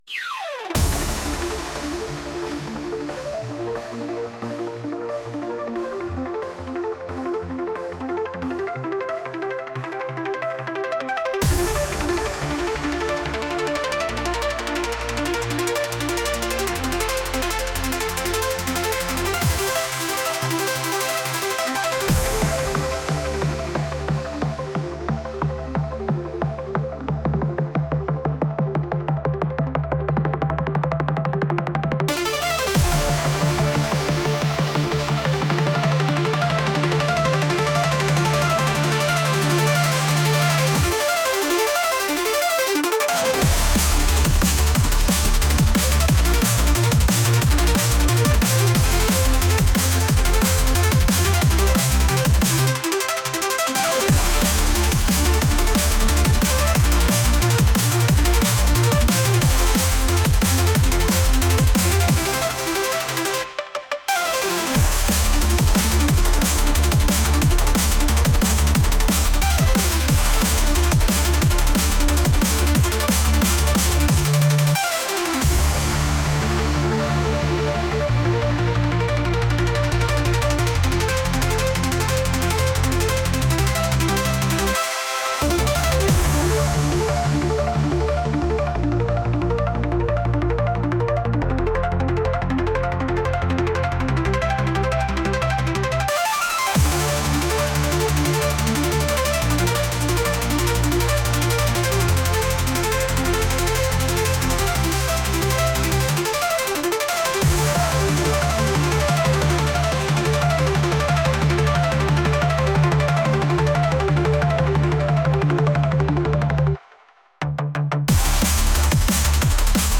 Retro Chiptune Game Intro Music